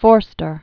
(fôrstər), E(dward) M(organ) 1879-1970.